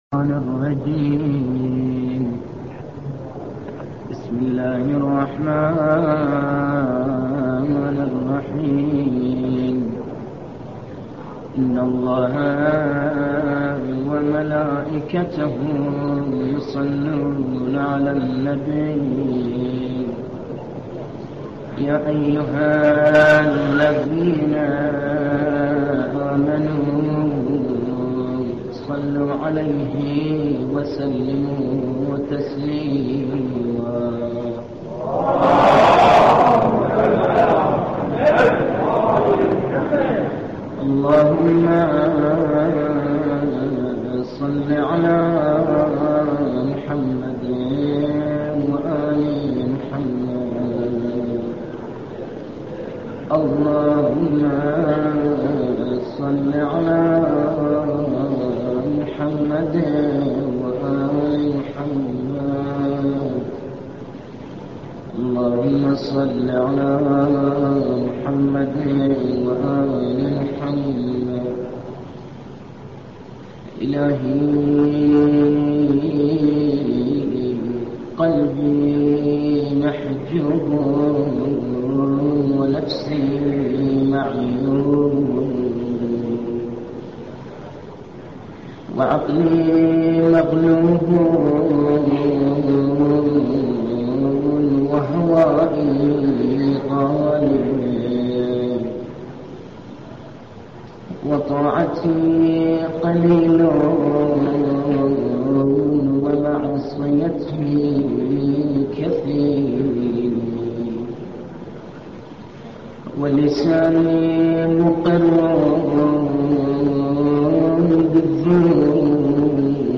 دعاء كميل بن زياد – حزين ومميز جدا